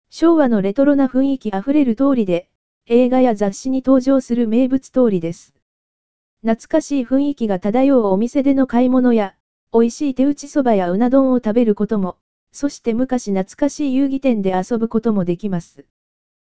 落合通り – 四万温泉音声ガイド（四万温泉協会）